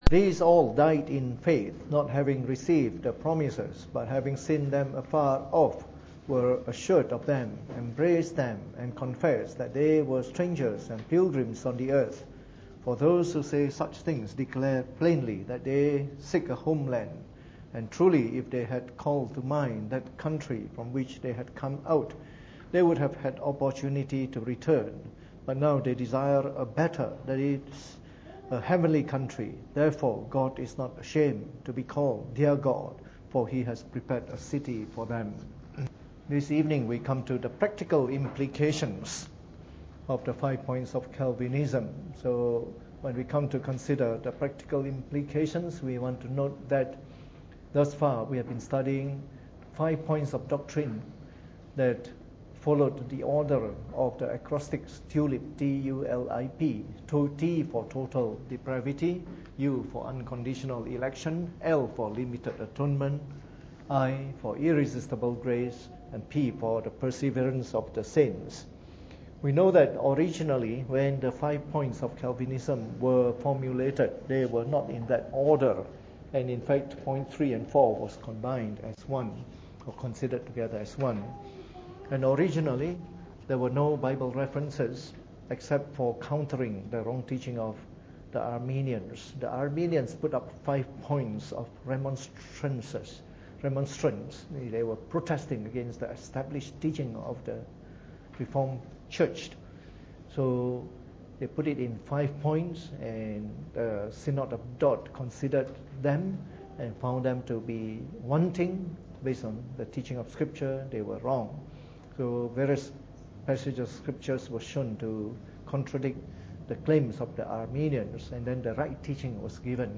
Preached on the 5th of October 2016 during the Bible Study, from our series on the Five Points of Calvinism.